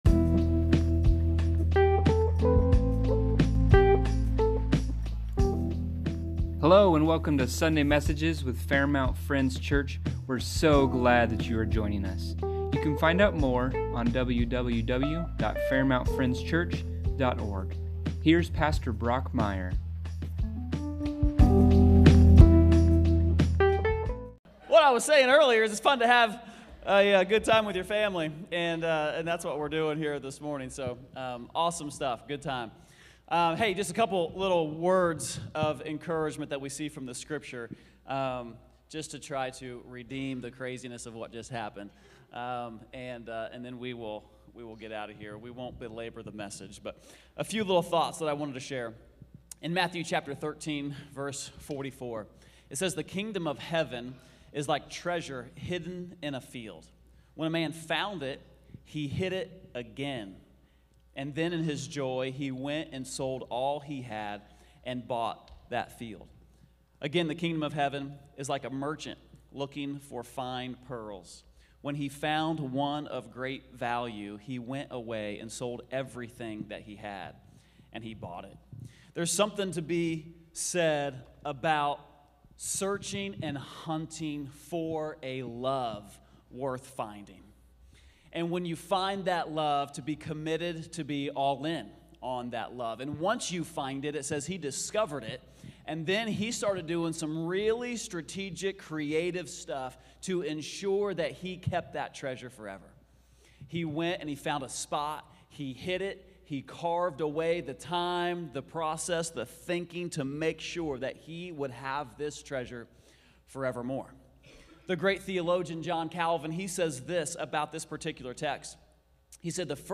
Sunday Messages | Fairmount Friends Church